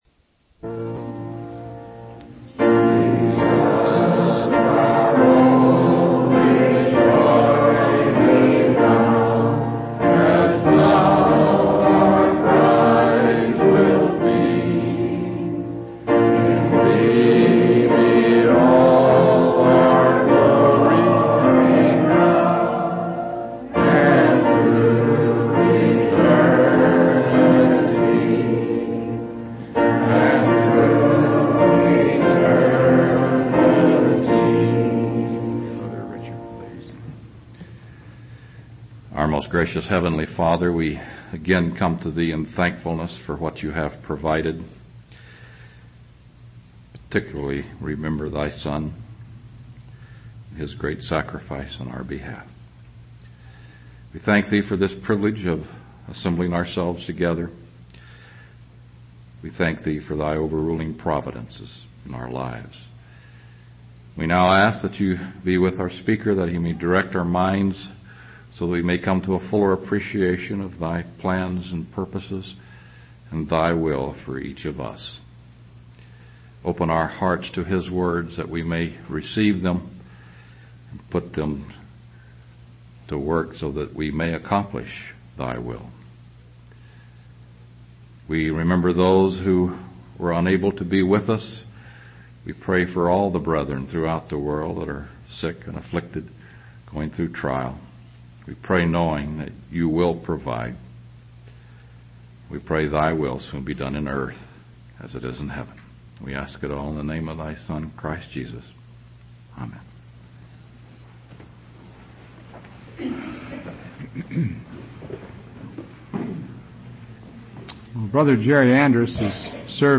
From Type: "Discourse"
Listen Given in Seattle WA 1992 Related Topics